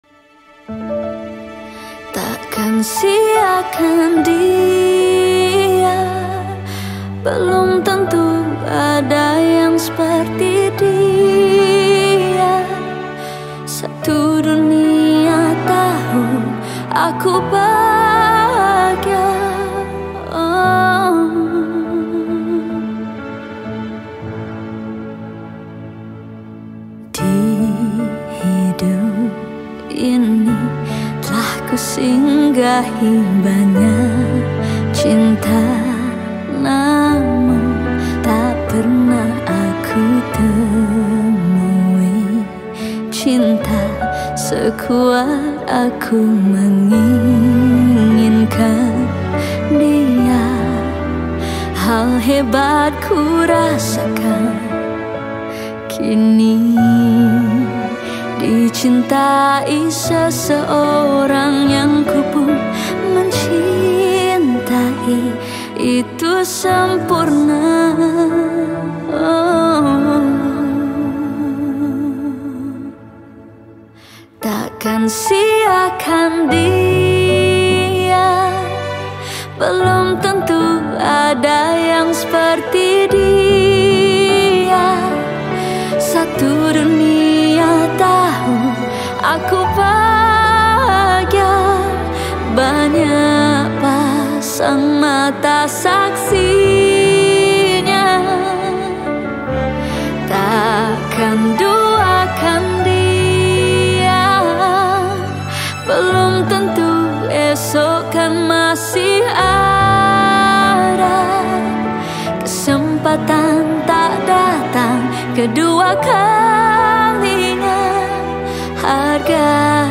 Malay Song
Skor Angklung